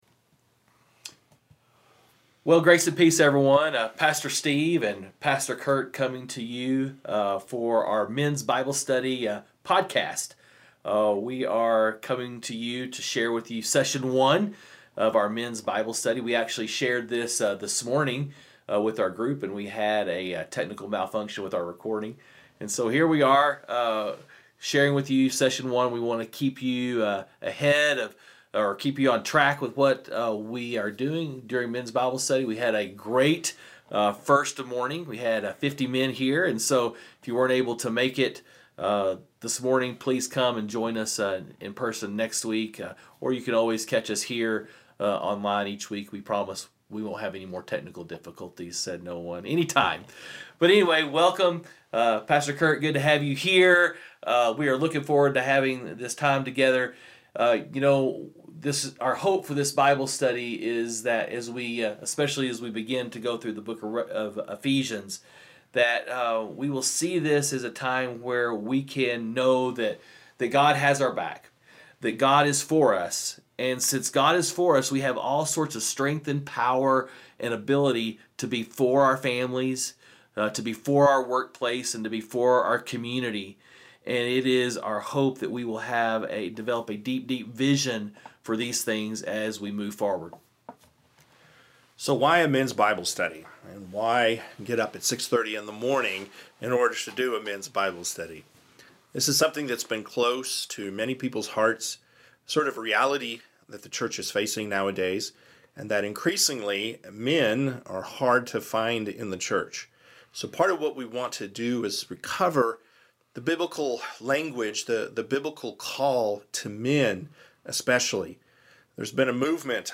Men’s Breakfast Bible Study 7/14/20